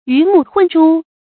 注音：ㄧㄩˊ ㄇㄨˋ ㄏㄨㄣˋ ㄓㄨ
魚目混珠的讀法